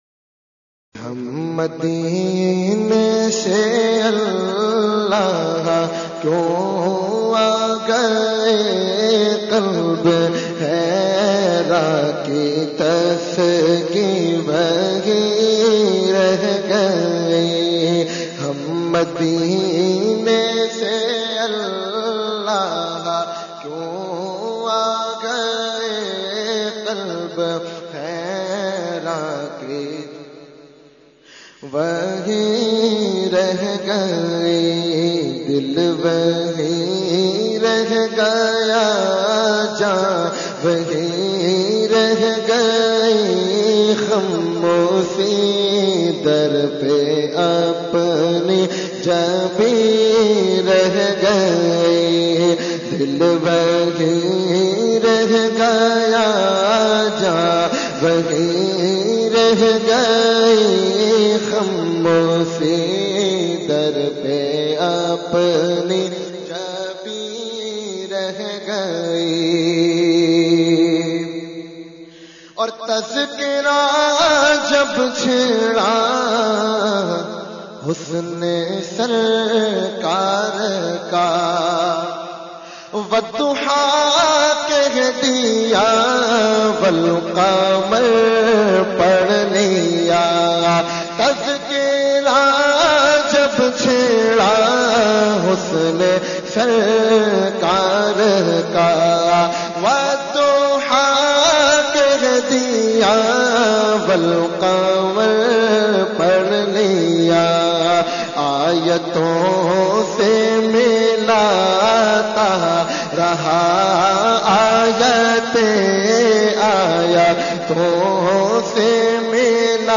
Category : Naat | Language : UrduEvent : 11veen Shareef 2018-2